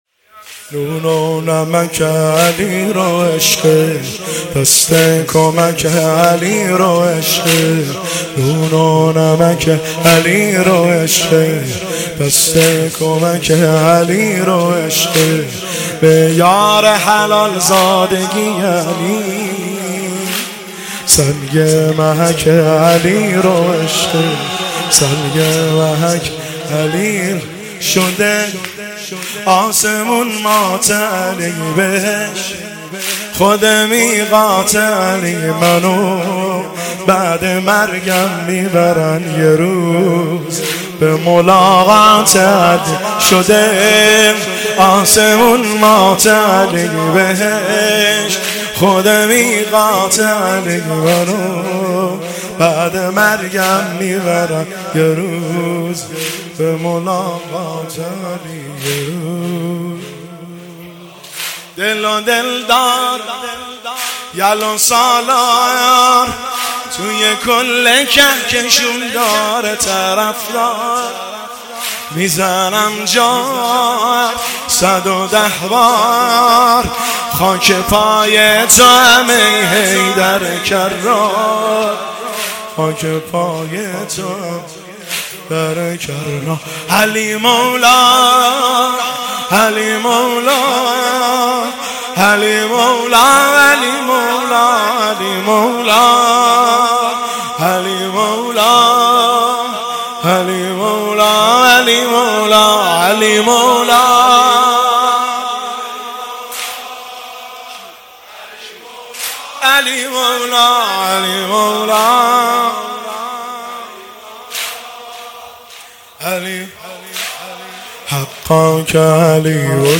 واحد شب دوم محرم الحرام 1403
هیئت عاشقان قمر بنی هاشم تهران